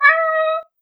めばえ４月号 ネコのなきごえ